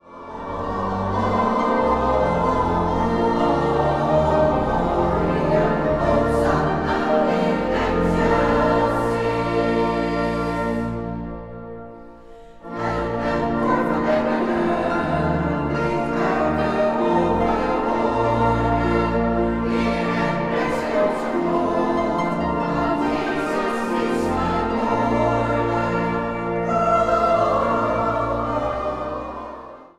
8 stemmen
Zang | Vrouwenkoor